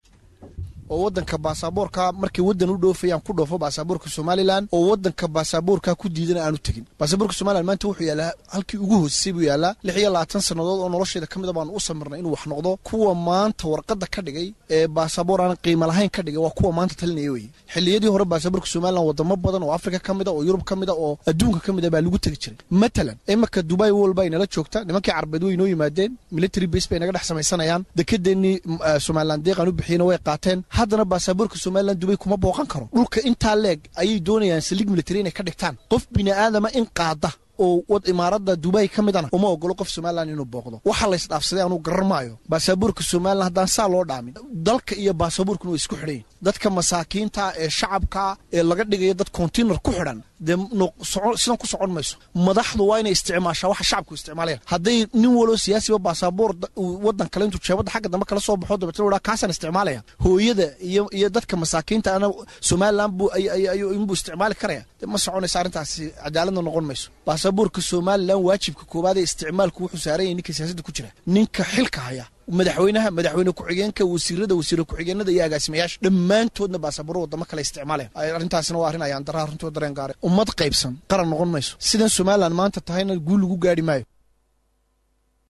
Wasiir ku xigeenkii Hore ee Wasaaradda qorsheynta Cabdikariin Axmed Mooge oo la hadlay Warbaahinta ayaa sheegay in Somaliland ay ku guuldareysatay in ay yeelato Baasaboor.